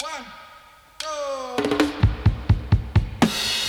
131-FILL-DUB.wav